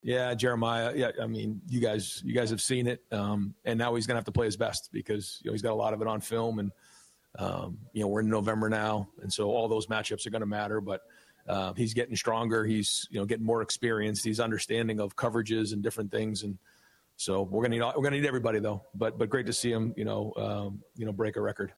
EXCERPTS FROM RYAN DAY’S POSTGAME PRESS CONFERENCE